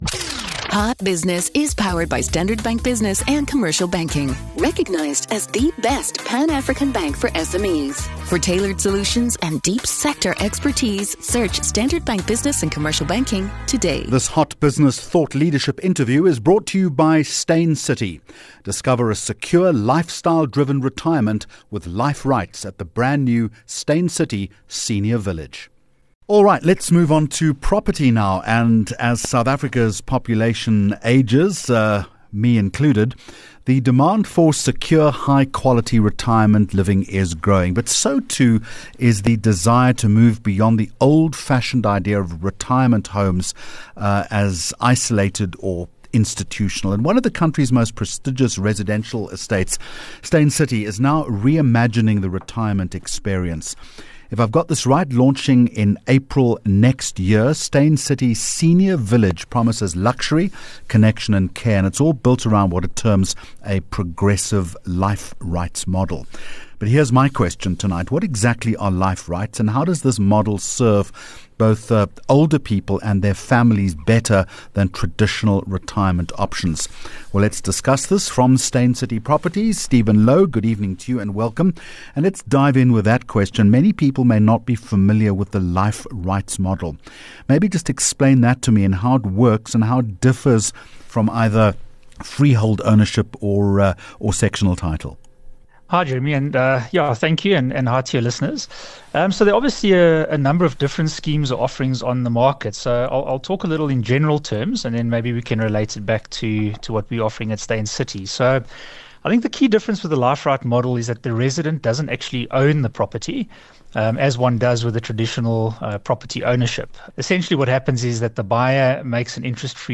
7 Jul Hot Business Interview